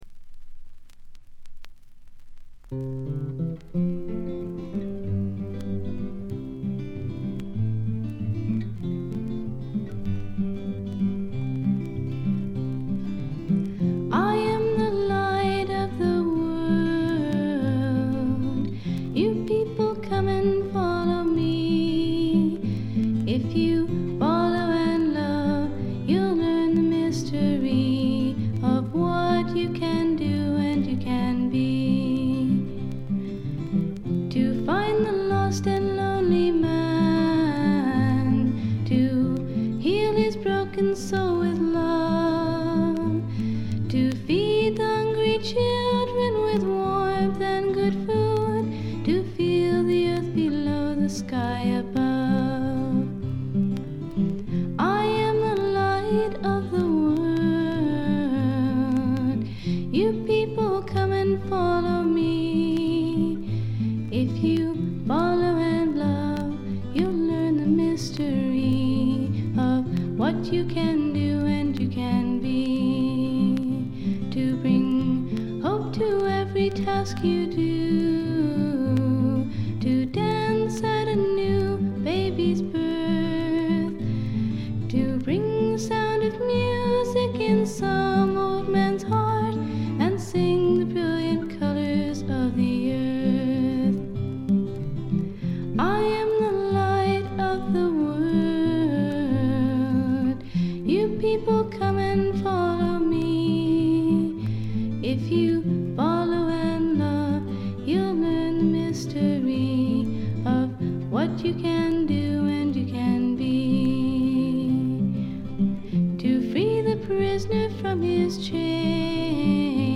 B1後半で3連プツ音。
演奏はほとんどがギターの弾き語りです。
試聴曲は現品からの取り込み音源です。